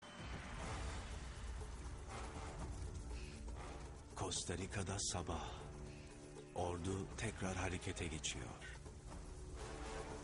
Рингтон себе на этот будильник поставлю.
У нас даже о нападении фашисткой Германии не таким страшным голосом объявляли, как он тут об утре в Коста Рике извещает.